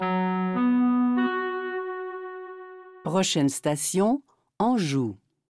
Listen to the métro voice pronounce the name Anjou